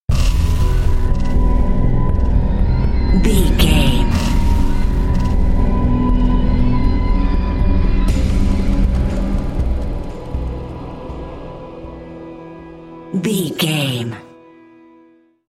Thriller
Aeolian/Minor
piano
synthesiser
percussion
ominous
dark
suspense
haunting
creepy